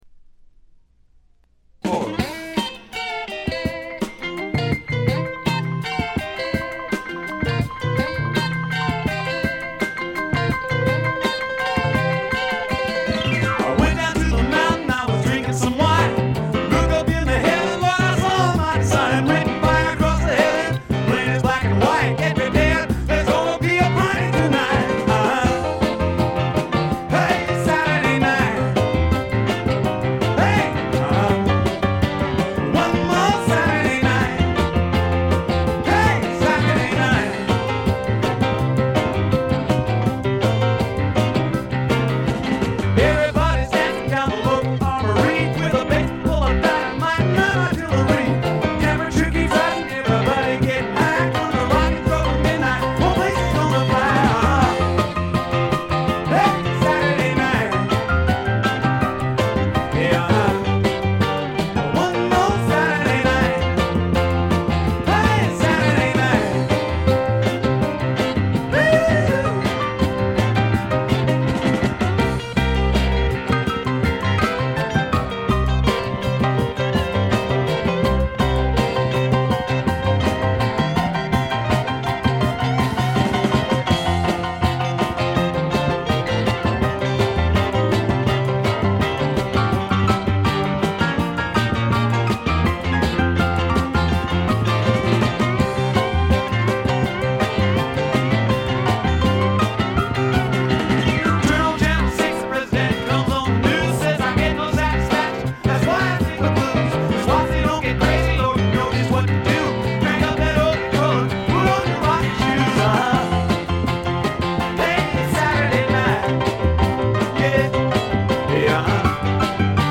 部分試聴ですが、微細なチリプチ程度でほとんどノイズ感無し。
試聴曲は現品からの取り込み音源です。